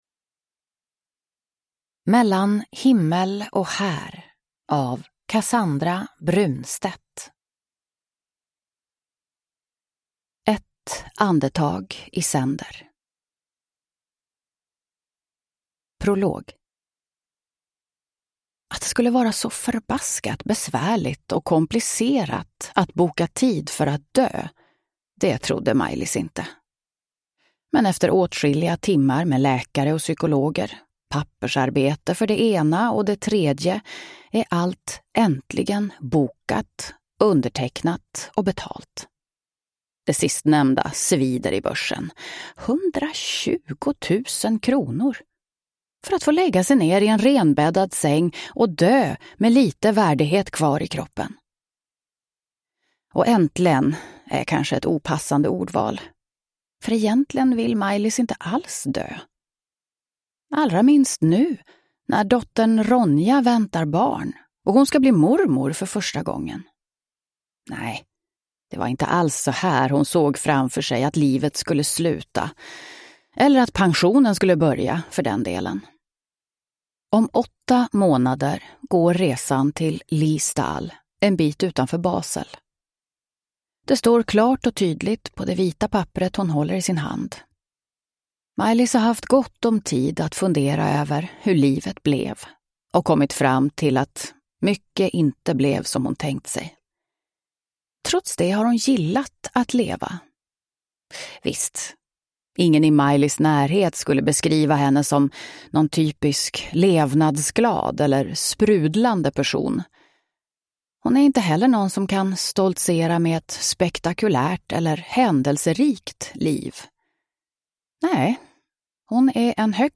Mellan himmel och här – Ljudbok